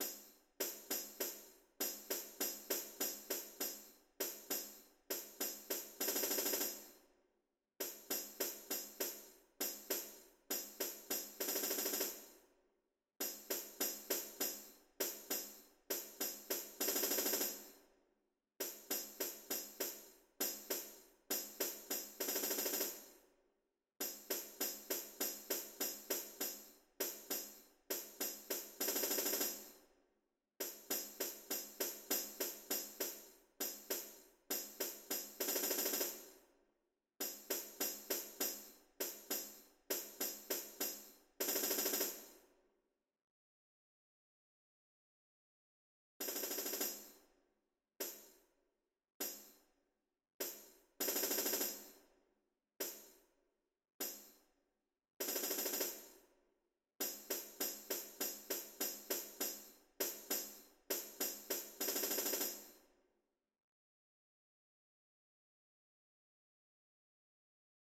Composer: Spanish Carol
Voicing: Brass Quartet